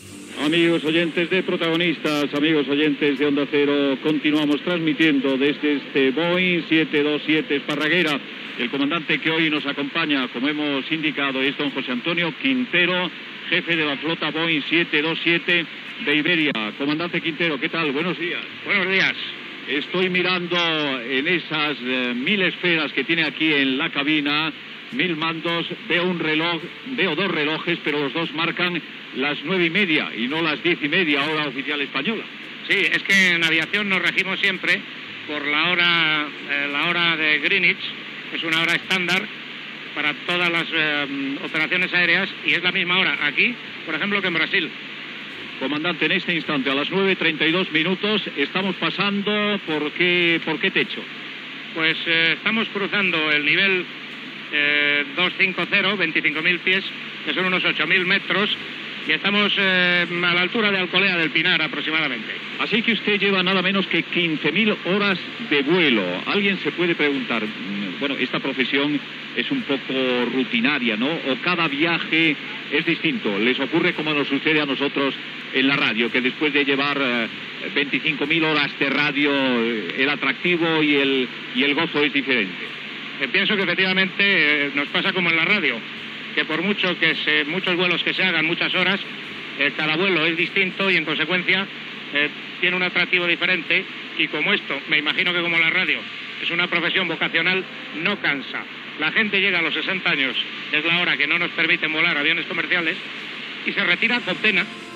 Programa emès des d'un Boeing 727 d'Iberia. Des del terminal del pont aeri Bardelona Madrid a l'aeroport de Barajas.
Info-entreteniment